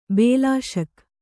♪ bēlāśal